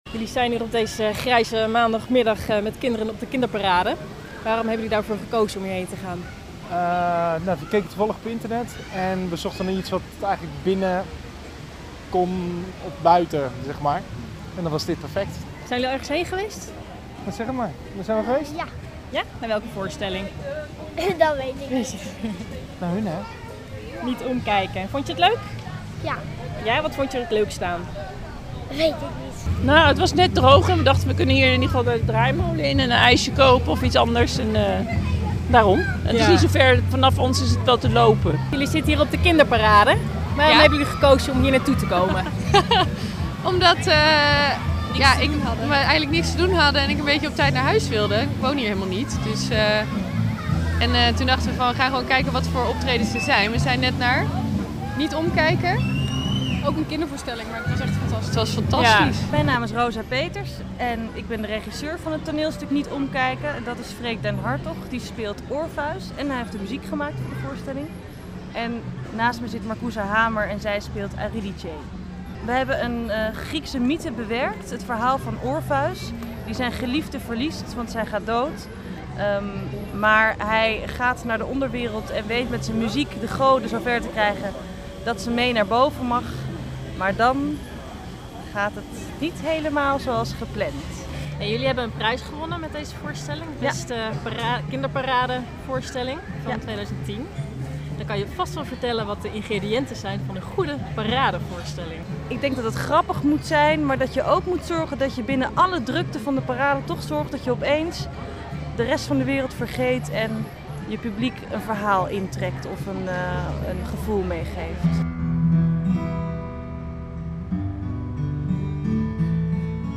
“Het is hier interessant”, zegt een mevrouw met een tweejarige dochter,”en goed voor haar ontwikkeling.
Ze de tent in krijgen en even de onrust van het terrein doen vergeten, dat is de taak van de theatermakers. De makers van Niet omkijken! vertellen hoe zij dat doen.